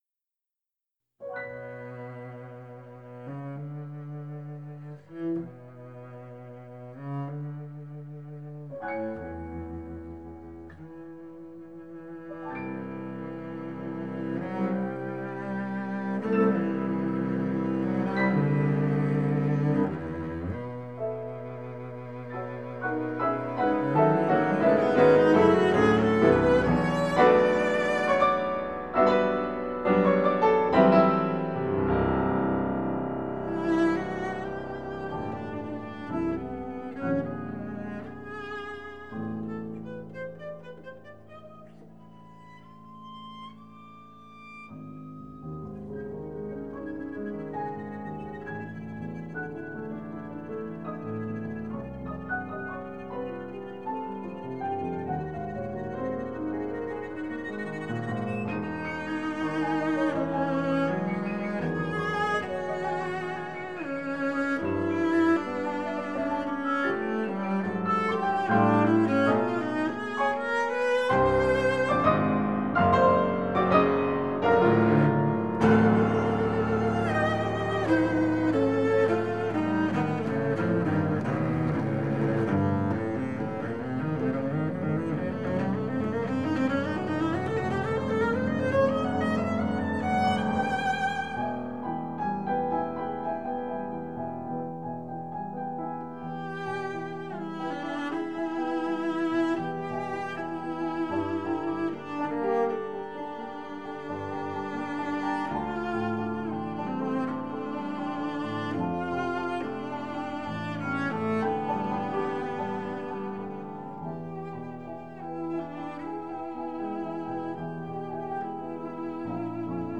cellist
pianist